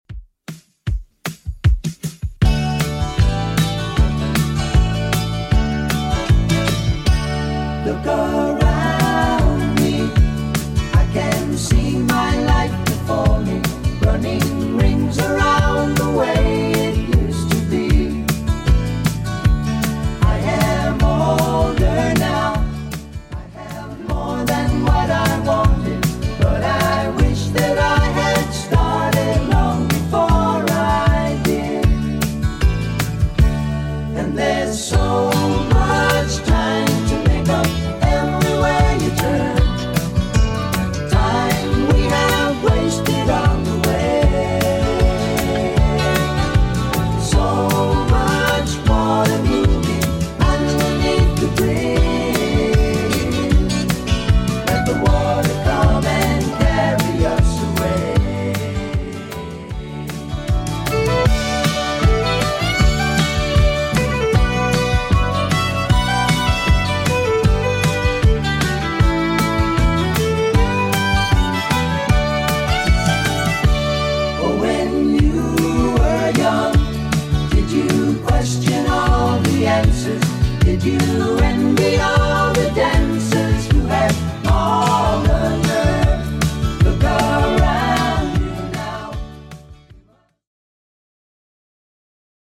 Genres: 2000's , ROCK
BPM: 84